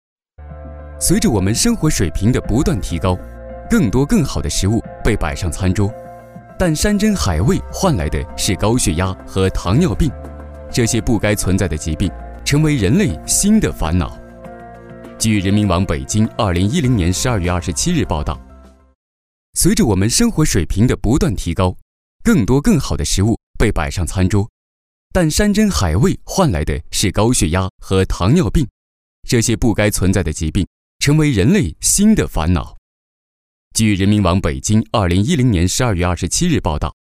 男国184年轻活力时尚配音-新声库配音网
男国184_专题_产品_医药消帮主_稳重.mp3